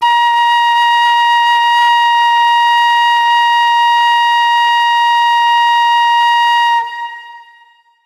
Thin Whistle tocando la nota B bemol
aerófono
celta
flauta